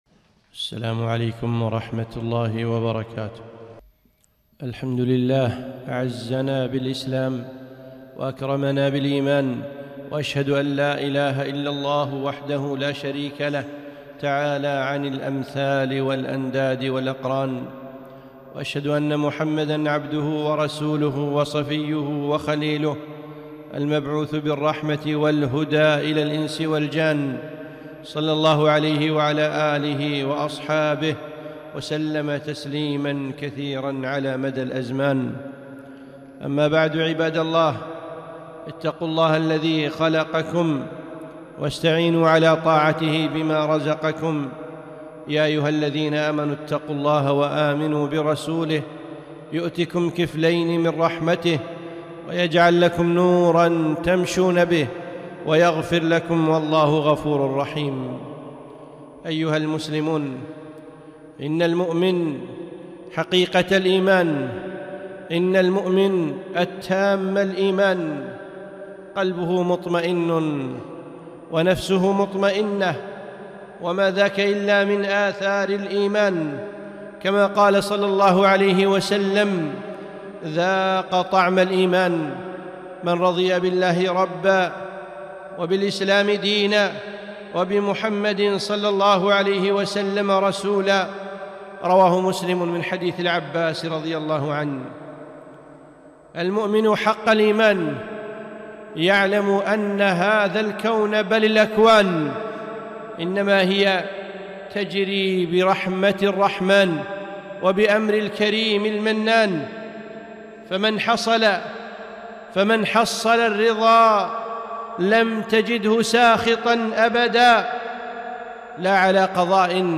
خطبة - طمأنينة القلب